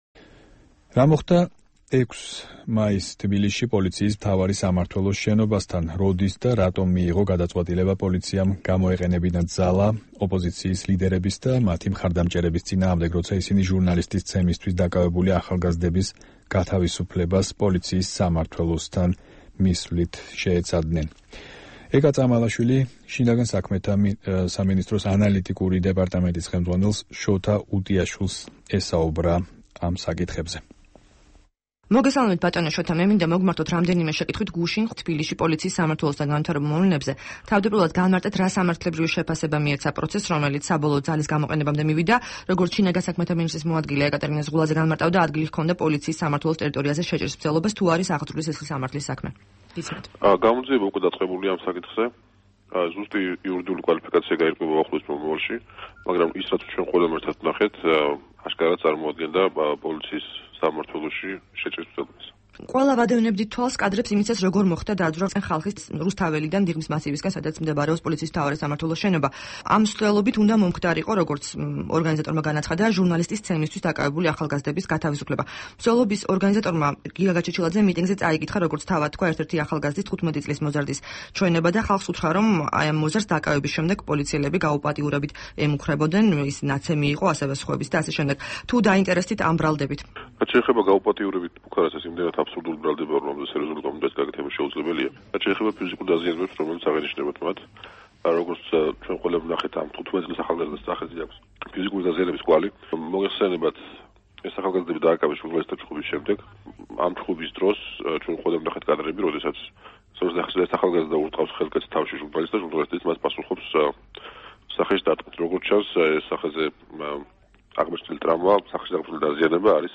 ინტერვიუ შოთა უტიაშვილთან